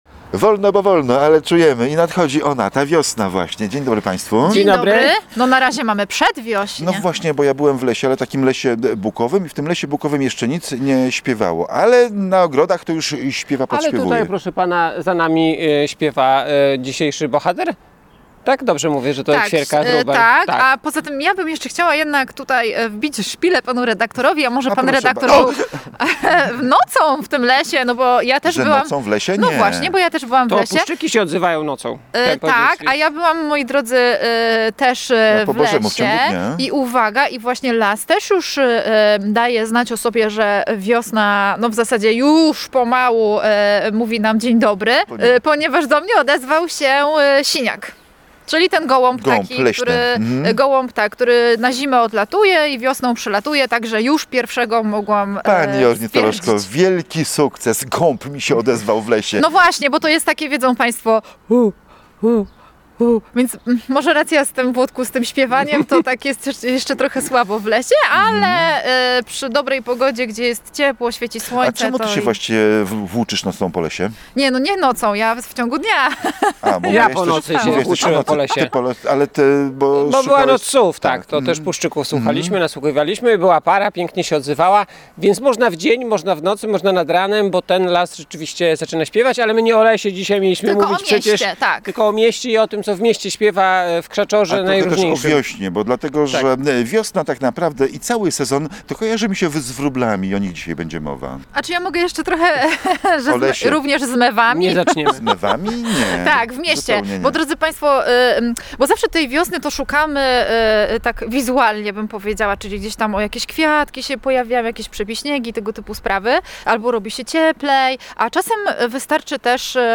Ta obserwacja była pretekstem do nagrania audycji o wróblach i wyjaśnienia, że ptaki spędzające zimę w dużych gromadach, teraz zaczęły mieć dostęp do pokarmu naturalnego i szukają miejsc do gniazdowania.